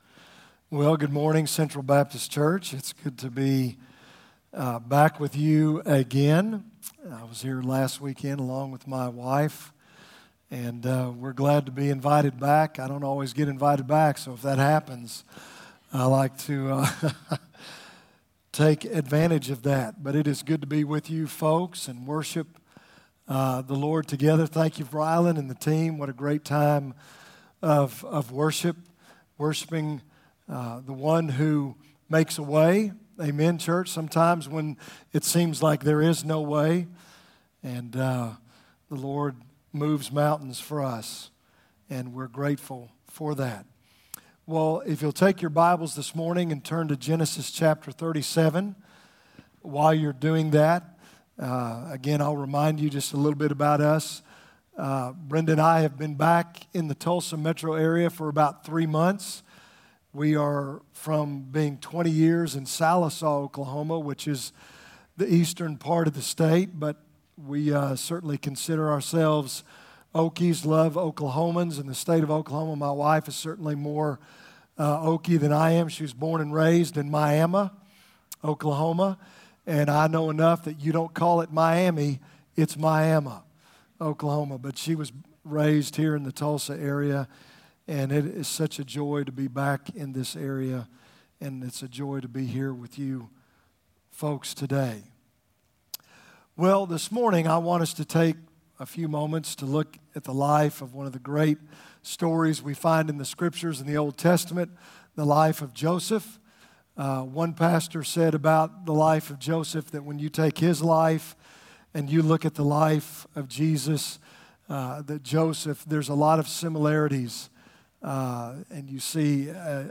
From Series: "2018 Sermons"